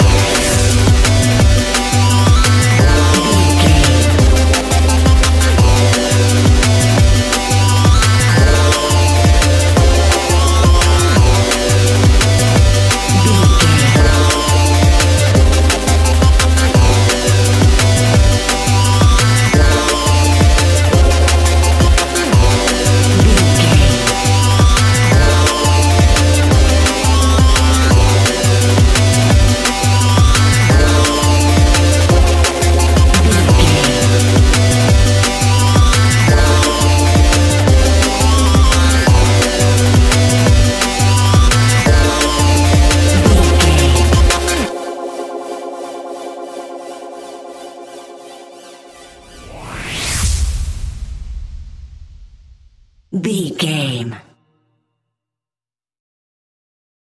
Ionian/Major
F♯
electronic
dance
techno
trance
synths
synthwave
instrumentals